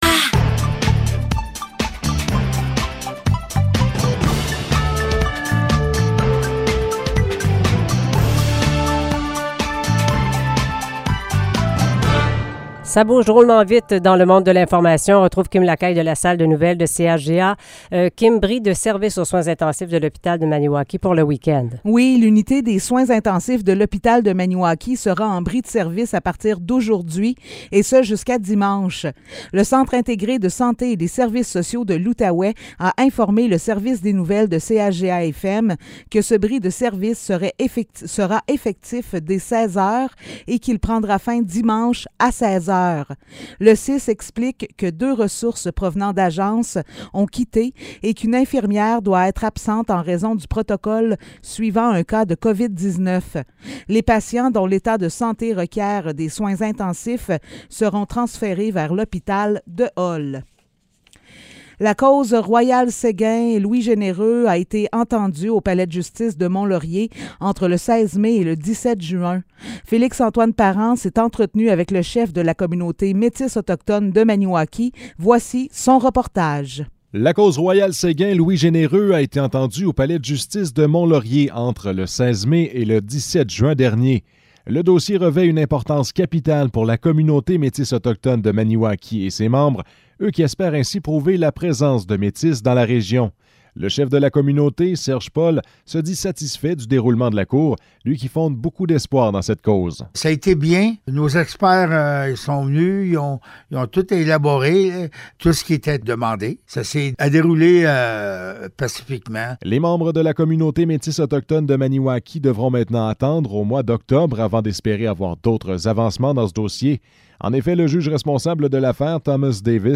Nouvelles locales - 22 juillet 2022 - 16 h